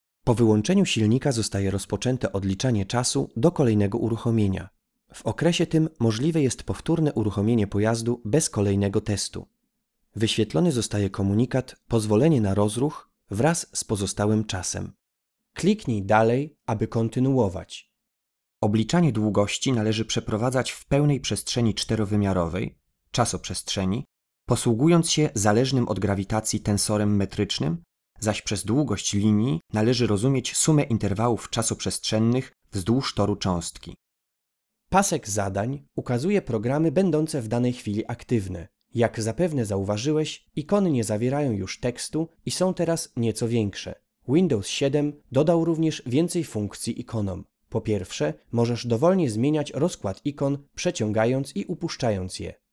Flexible Tonfärbung.
Sprechprobe: eLearning (Muttersprache):